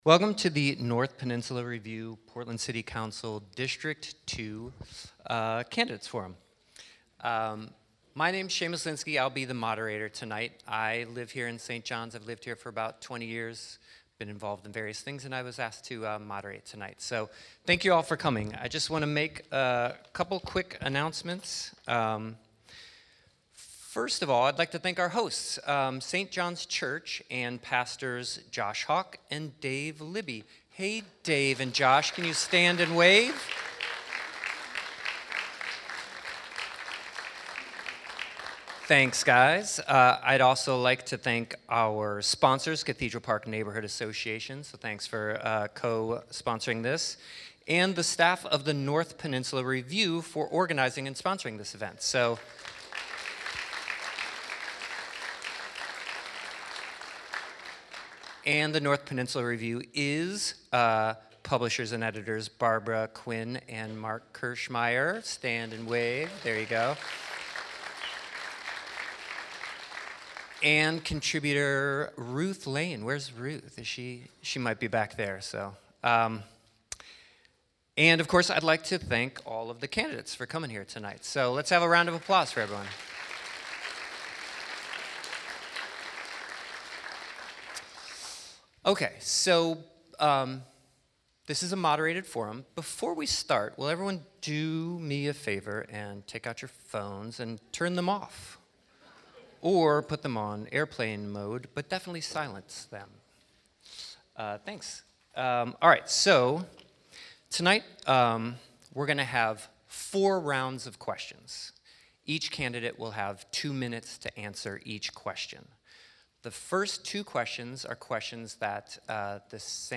This show contains excerpts from a Portland City Council District 2 candidate forum.